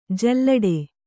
♪ jallaḍe